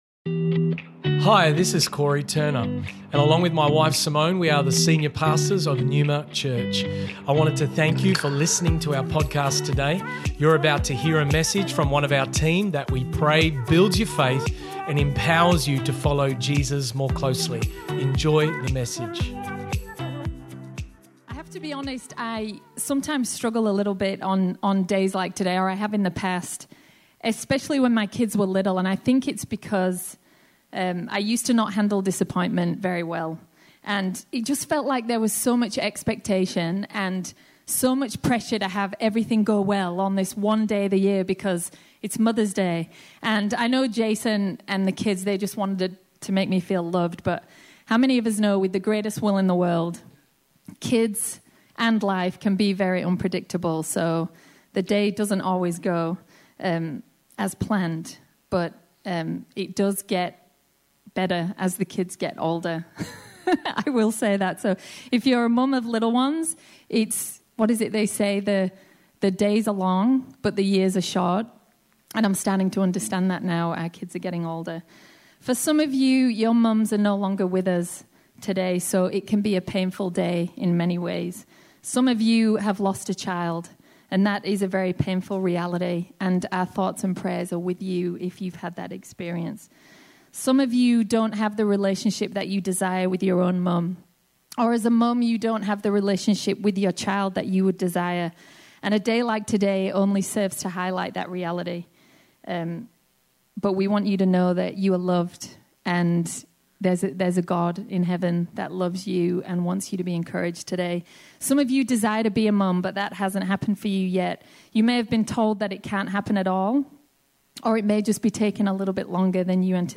This message was originally preached at NEUMA Church Perth on Mother's Day - Sunday, 9th May 2021.